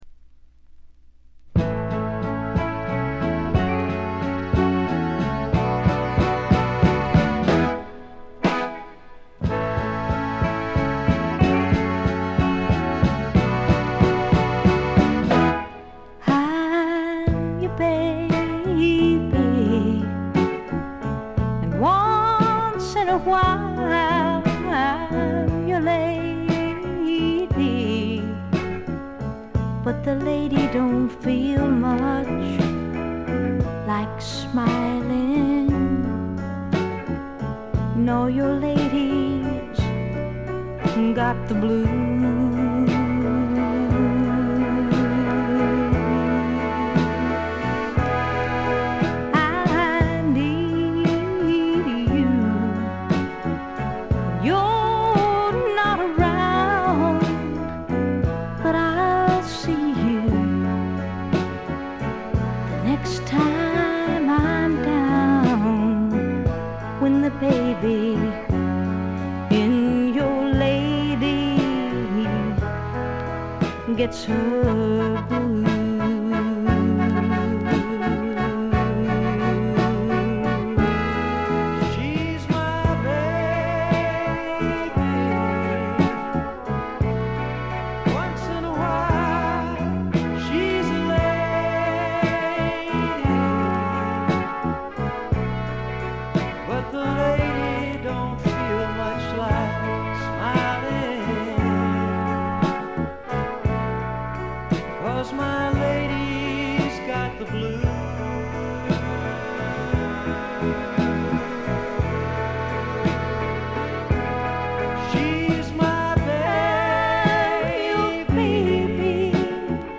バックグラウンドノイズがほぼ常時出ており静音部でやや目立ちます。
A面ラストはちょっとファニーでスウィートでとろけるヴォーカルがたまらない味を醸し出しています。
試聴曲は現品からの取り込み音源です。
Guitar, Harmonica, Vocals